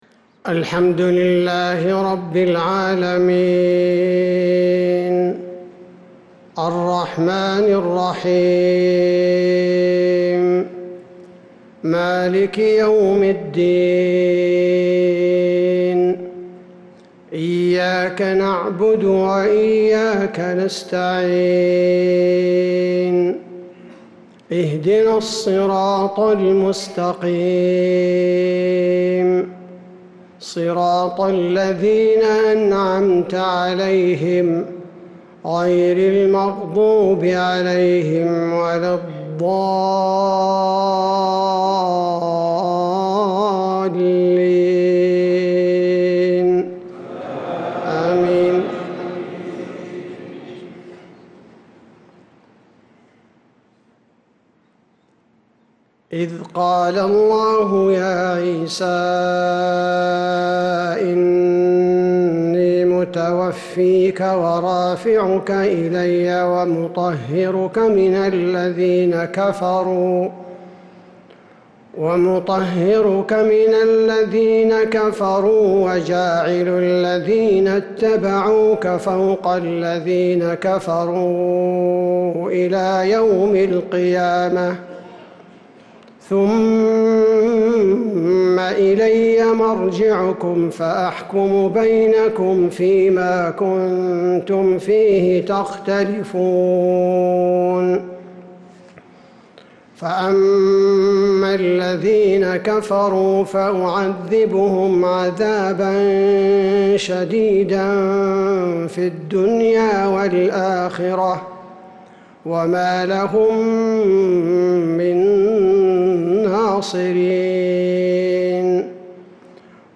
مغرب الأربعاء 12 صفر 1447هـ | من سورتي آل عمران 55-62 و الإخلاص | maghrib prayer from surat Al-Imran and al-iklas 6-8-2025 > 1447 🕌 > الفروض - تلاوات الحرمين